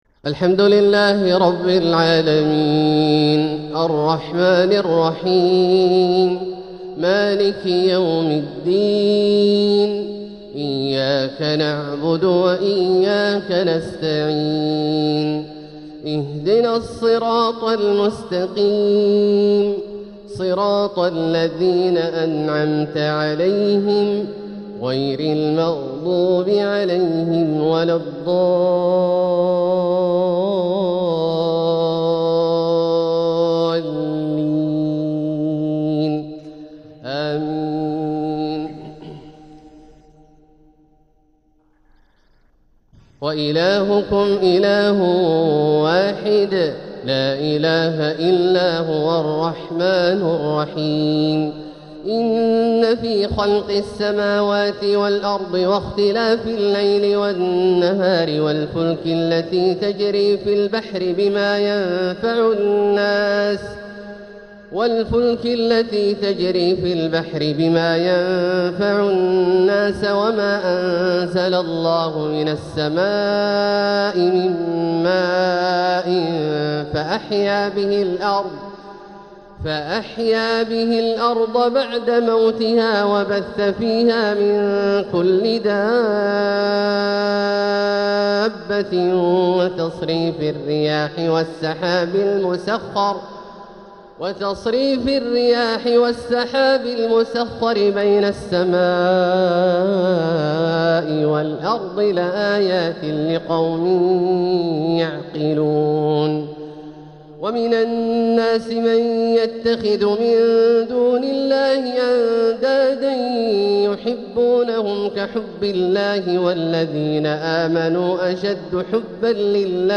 تلاوة من سورة البقرة 163-177 | فجر الأحد 1 ربيع الأول 1447هـ > ١٤٤٧هـ > الفروض - تلاوات عبدالله الجهني